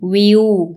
– wiuu